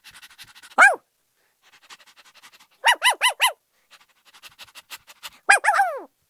dog_bark_three_times.ogg